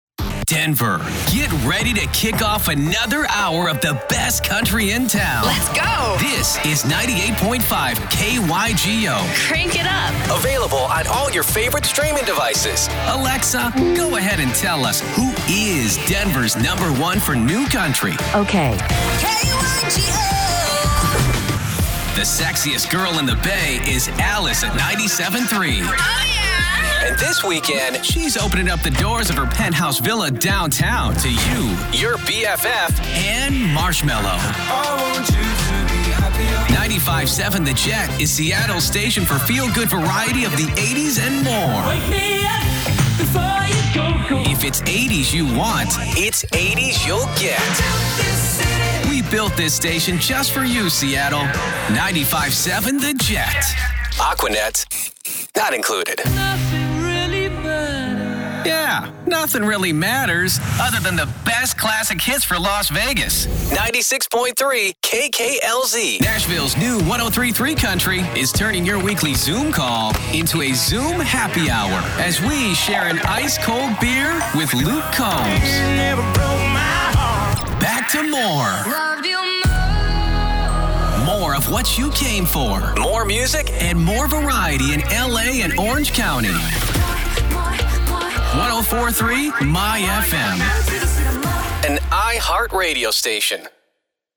Guy next door, Engaging, Friendly, Conversational, Real, Believable, Upbeat, Energetic, Warm, Witty, Sincere, Sarcastic, Confident
Radio Imaging